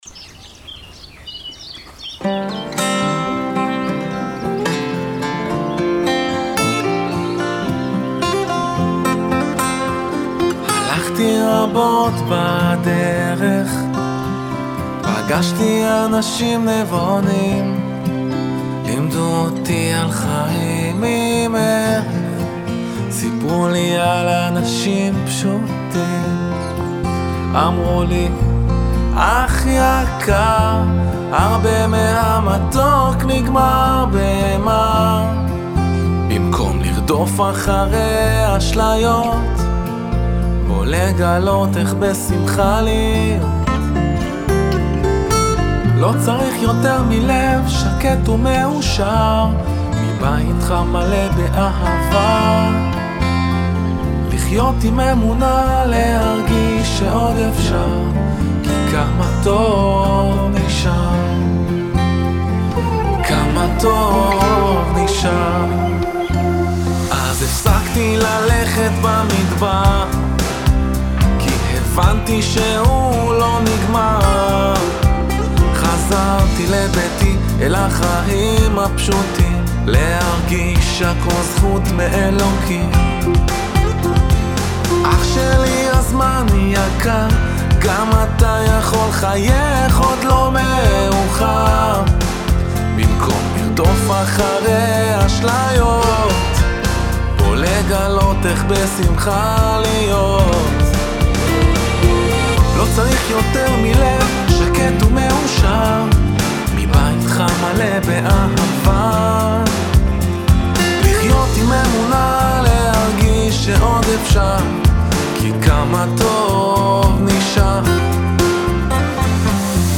זה לפני מיקס והמאסטר שם הוא חובבני אבל זה עדייין נשמע טוב).